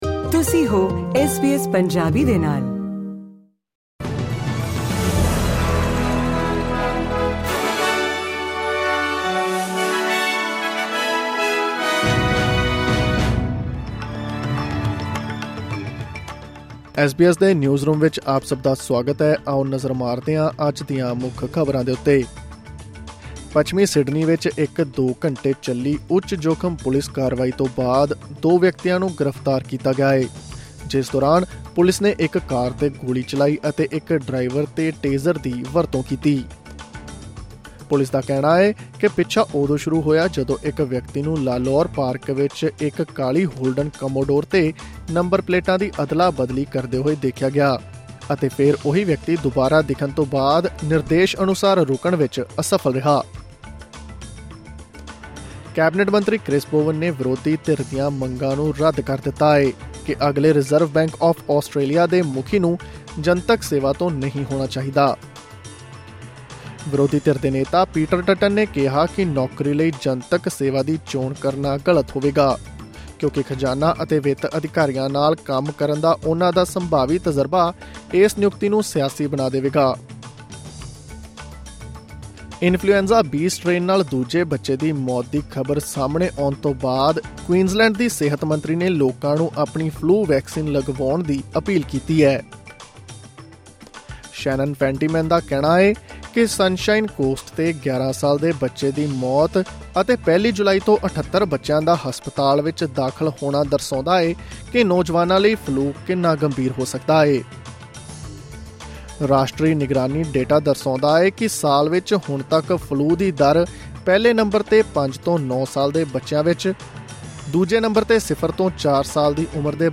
ਐਸ ਬੀ ਐਸ ਪੰਜਾਬੀ ਤੋਂ ਆਸਟ੍ਰੇਲੀਆ ਦੀਆਂ ਮੁੱਖ ਖ਼ਬਰਾਂ: 13 ਜੁਲਾਈ, 2023